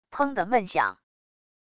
砰的闷响wav音频